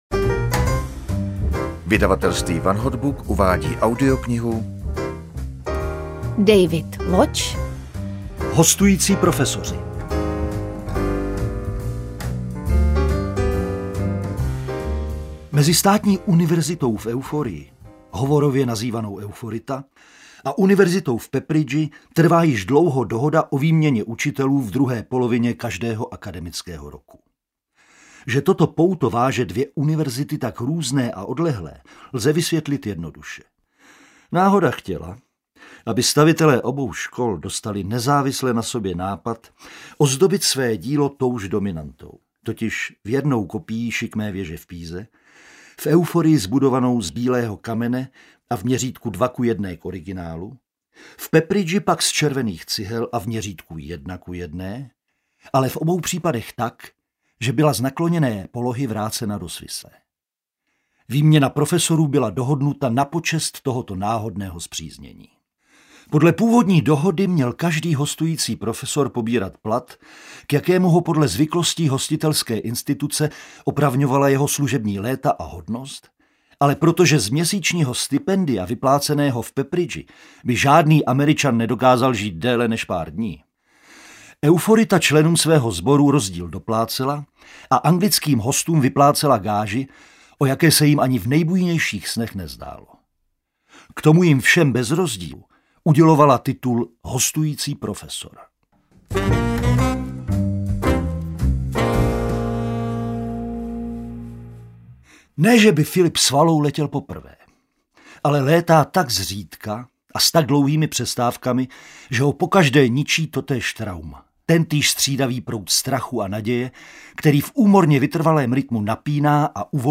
Hostující profesoři audiokniha
Ukázka z knihy